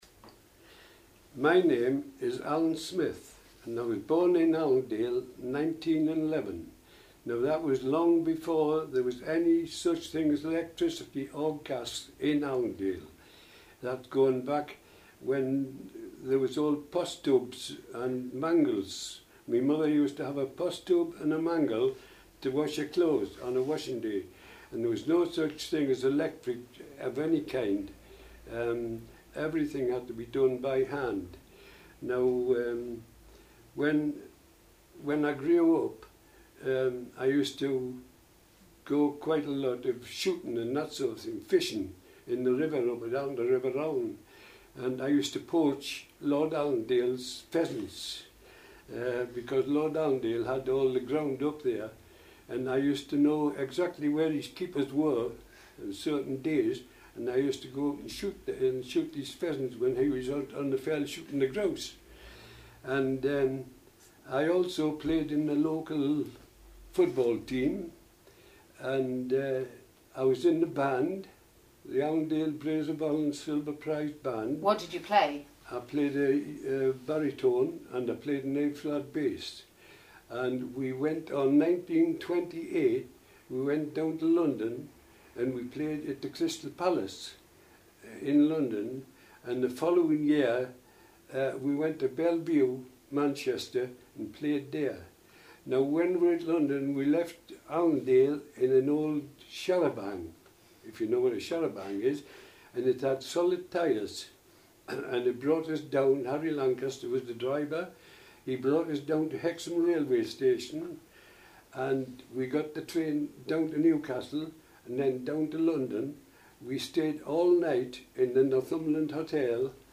Everyday Life in a Northumbrian Manor:Animating the Manor - Learning Resources Oral Histories ..